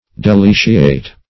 Search Result for " deliciate" : The Collaborative International Dictionary of English v.0.48: Deliciate \De*li"ci*ate\, v. t. To delight one's self; to indulge in feasting; to revel.